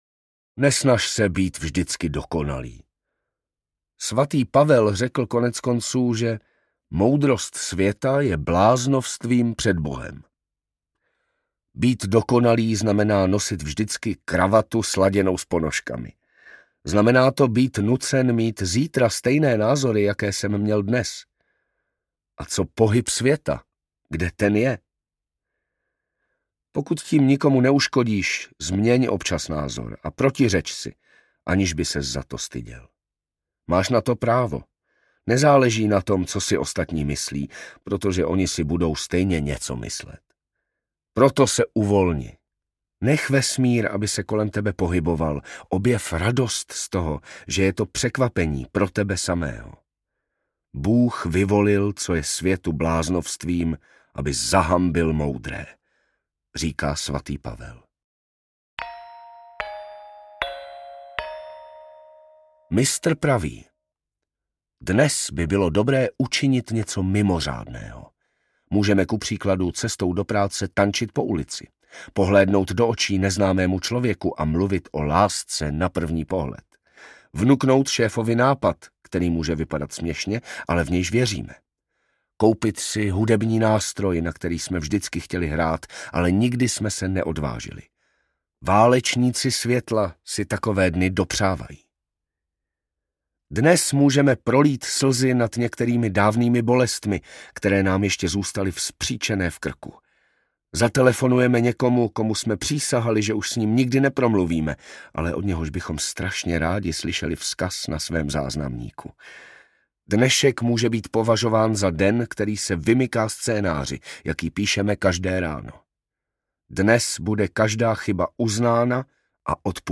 Maktub audiokniha
Ukázka z knihy